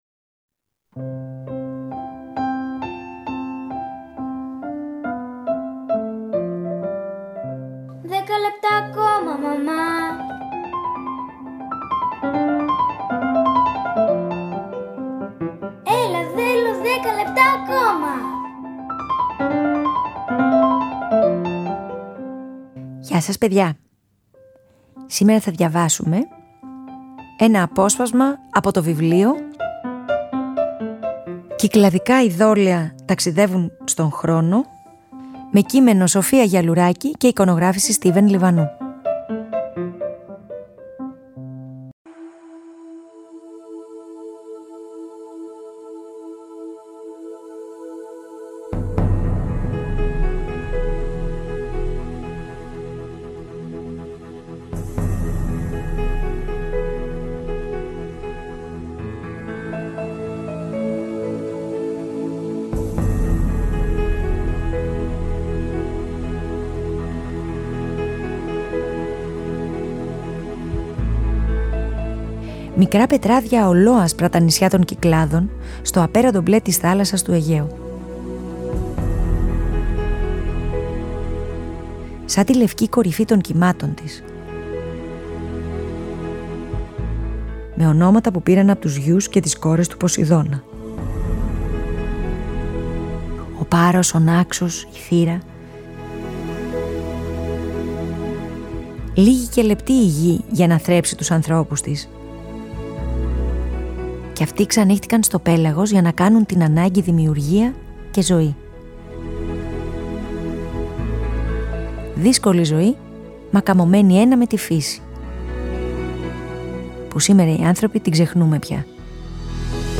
Διαβάζουμε ένα απόσπασμα από ένα βιβλίο που παρουσιάζει 27 κυκλαδικά ειδώλια και αντικείμενα από τις Κυκλάδες και την Αττική, έργα τέχνης περιόδων από το 3200 μέχρι το 2000 π.Χ. Προέρχονται κυρίως από το Μουσείο Κυκλαδικής Τέχνης, αλλά και από το Αρχαιολογικό Μουσείο Νάξου ή το Εθνικό Αρχαιολογικό Μουσείο στην Αθήνα.
Philip Glass, The Truman Show (ost)